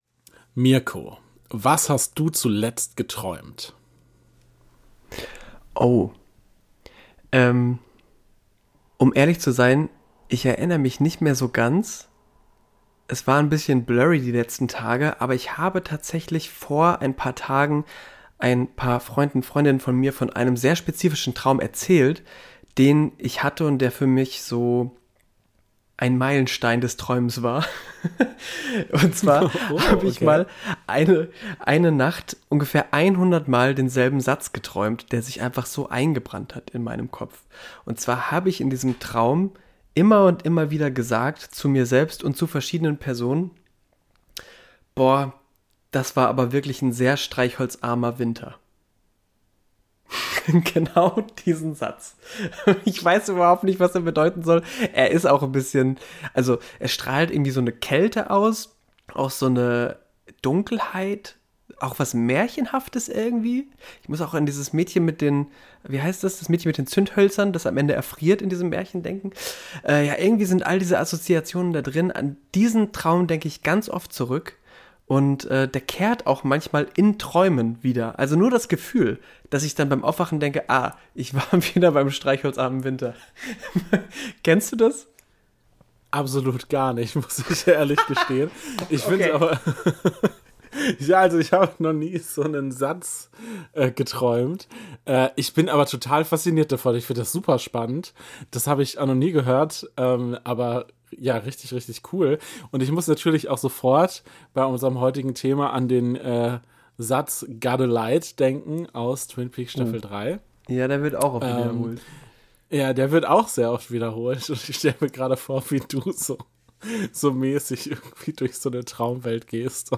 Es knistert, zischt, klirrt und wummert unbehaglich, stroboskop Lichter erleuchten die in Dampf verhüllten Umgebungen und irgendwo im Hintergrund hören wir einen markerschütternden Schrei.